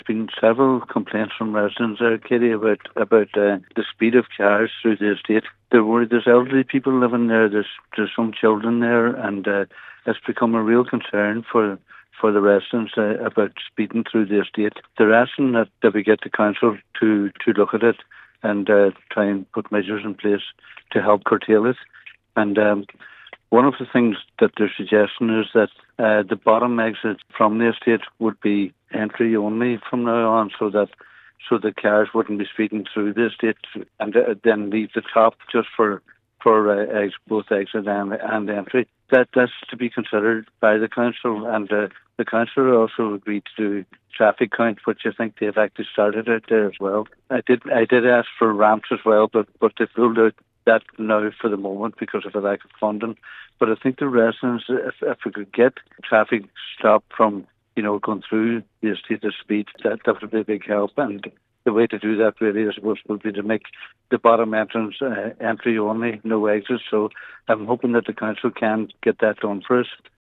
Councillor Kavanagh says there is a genuine fear that someone will be seriously injured by a speeding vehicle or worse: